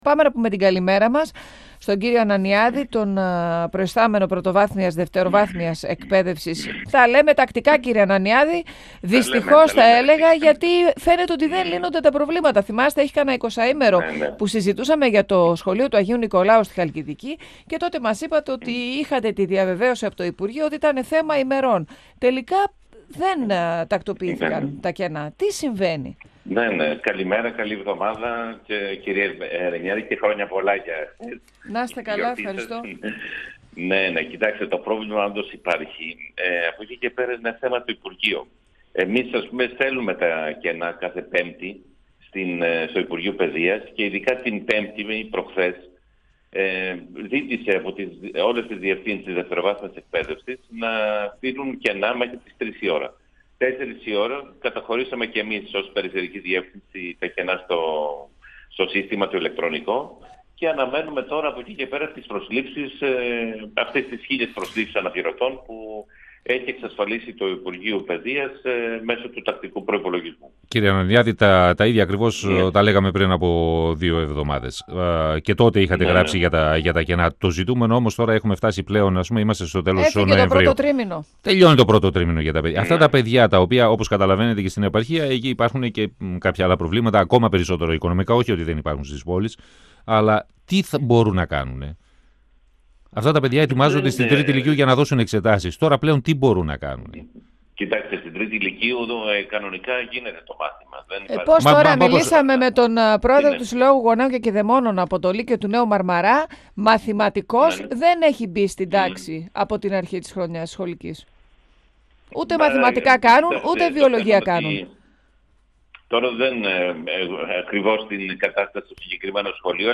επικεφαλής της Διεύθυνσης Πρωτοβάθμιας και Δευτεροβάθμιας Εκπαίδευσης Κεντρικής Μακεδονίας Παναγιώτης Ανανιάδηςστον 102FM του Ρ.Σ.Μ. της ΕΡΤ3
Η τοποθέτηση εκπαιδευτικών είναι θέμα του υπουργείου Παιδείας, ανέφερε ο επικεφαλής της περιφερειακής Διεύθυνσης Πρωτοβάθμιας και Δευτεροβάθμιας Εκπαίδευσης Κεντρικής Μακεδονίας, Παναγιώτης Ανανιάδης, μιλώντας στον 102FM της ΕΡΤ3. Ο κ. Ανανιάδης διευκρίνισε ότι τα κενά είναι γνωστά στο υπουργείο και υποστήριξε ότι τα προβλήματα προκλήθηκαν και από την αδυναμία ένταξης της Δευτεροβάθμιας Εκπαίδευσης σε προγράμματα ΕΣΠΑ.